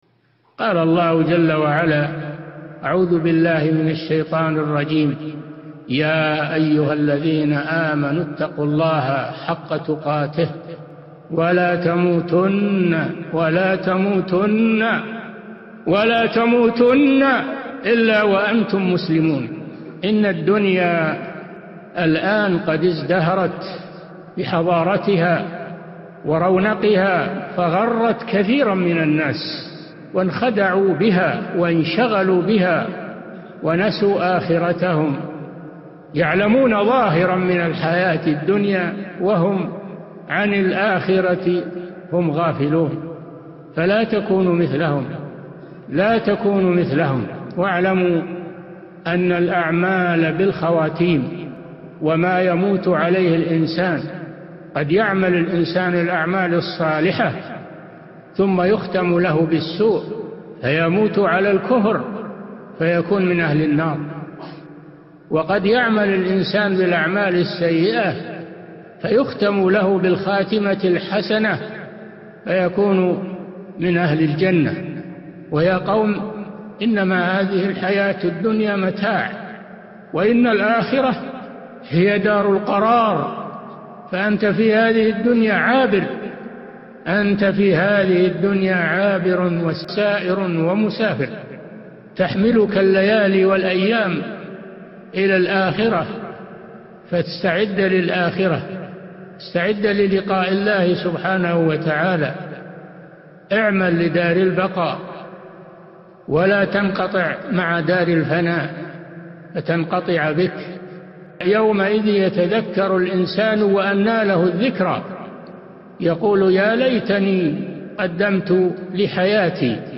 موعظة-الدنيا-ازدهرت-بحضارتها-وبهجتها-وغرت-كثيرا-من-الناس.mp3